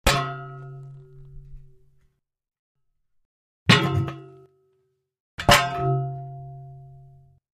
Shovel, Metal, Hits, Heavy x3